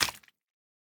Minecraft Version Minecraft Version latest Latest Release | Latest Snapshot latest / assets / minecraft / sounds / block / beehive / drip4.ogg Compare With Compare With Latest Release | Latest Snapshot
drip4.ogg